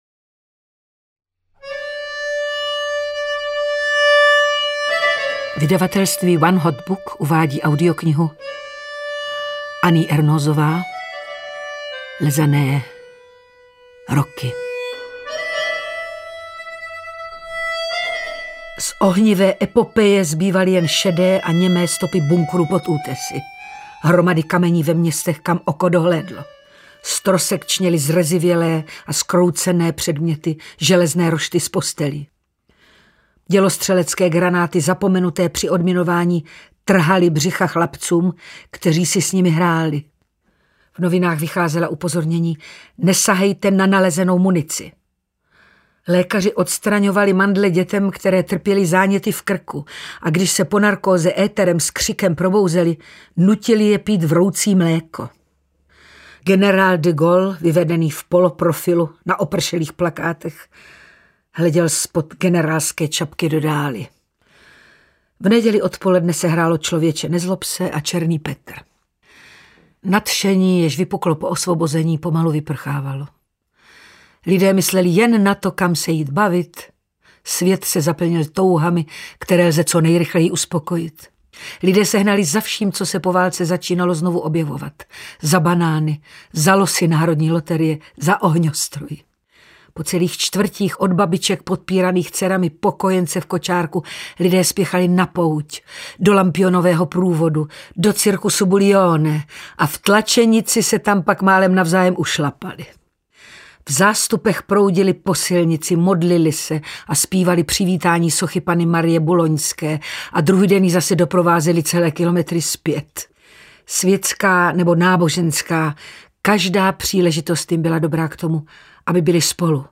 Roky audiokniha
Ukázka z knihy
• InterpretEliška Balzerová